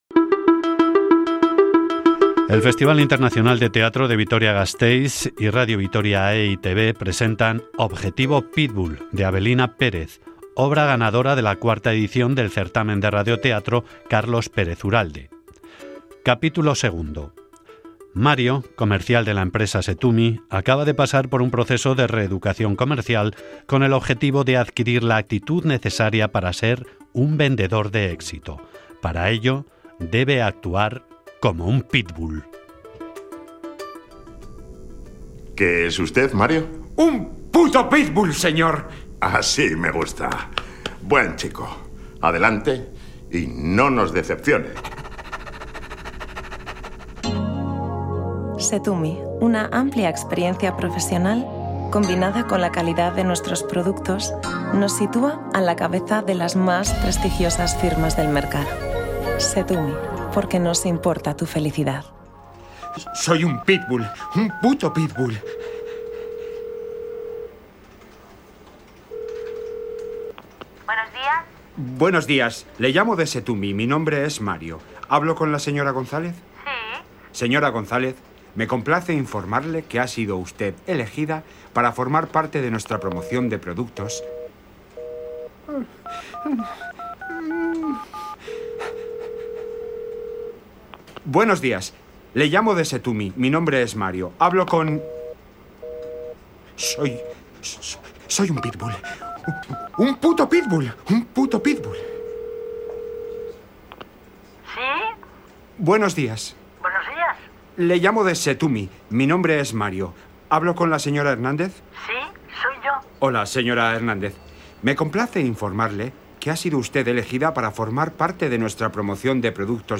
Radionovela
Grabado en Sonora Estudios.